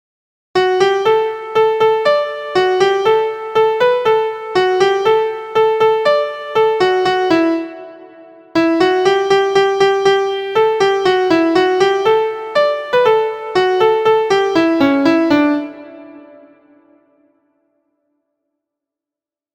Rhythm changes to match verse syllables.
• Origin: United States of America – Traditional
• Key: D Major
• Time: 2/4
• Form: ABCD
• Musical Elements: notes: quarter, eighth, dotted eighth, sixteenth; rest: quarter; pickup beat, fermata, tied notes, vocal slur